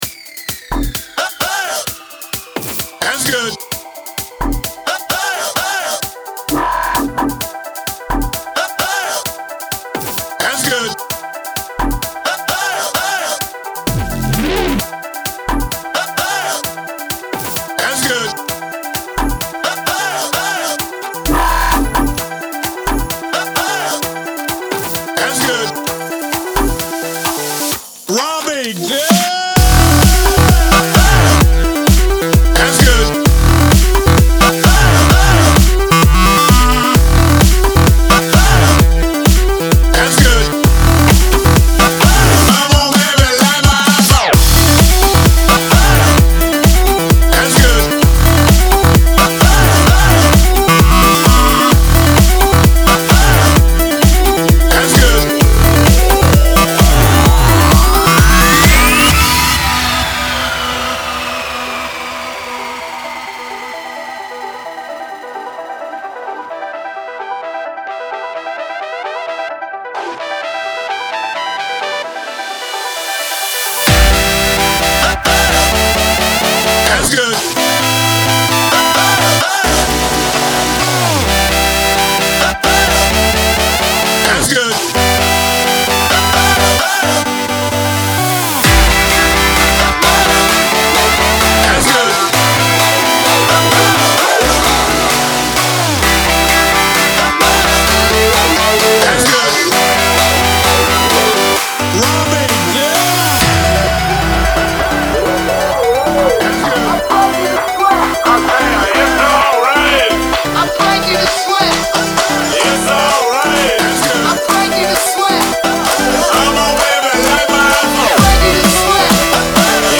Genre: Electronic